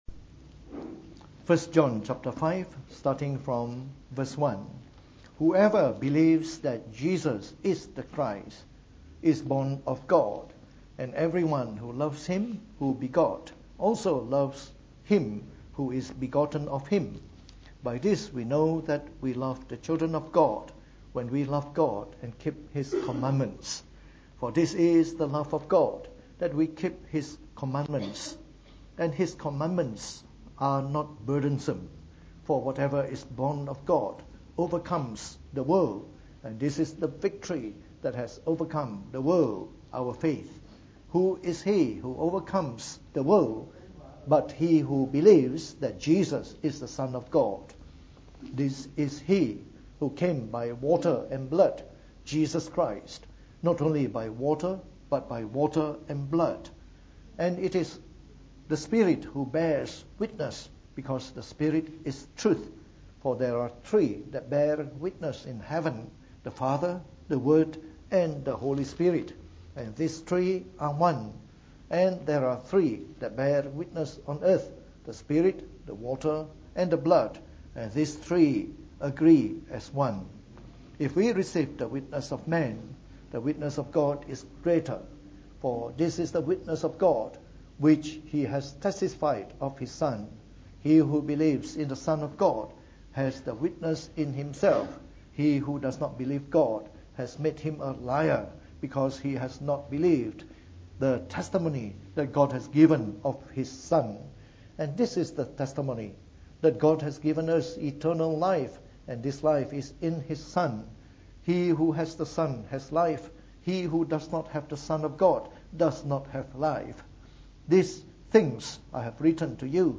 From our series on the Book of 1 John delivered in the Morning Service.